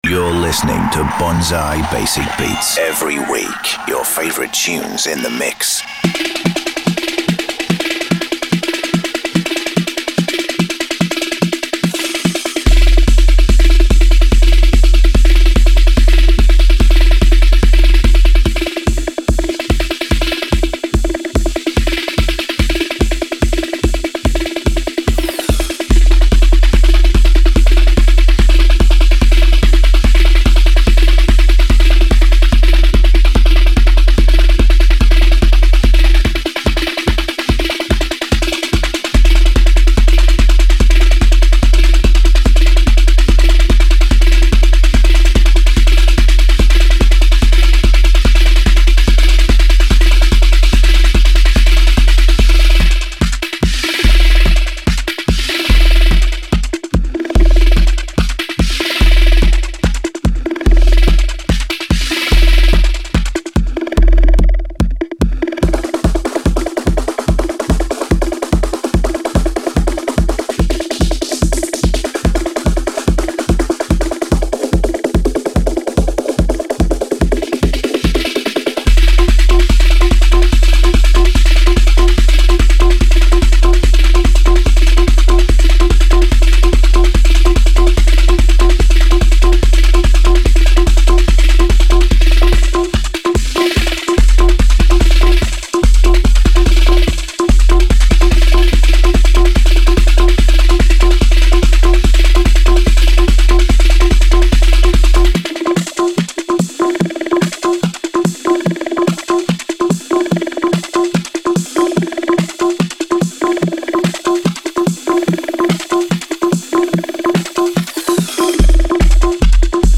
DJ booth